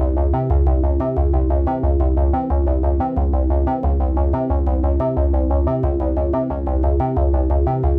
Index of /musicradar/dystopian-drone-samples/Droney Arps/90bpm
DD_DroneyArp4_90-C.wav